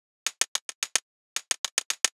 UHH_ElectroHatC_110-02.wav